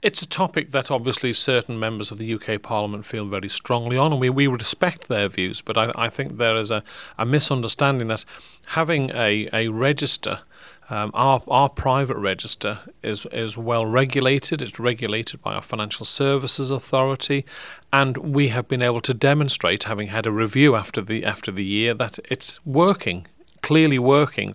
The matter came up during the Sanctions and Anti-Money Laundering Bill debate in the House of Commons on Tuesday - Howard Quayle:-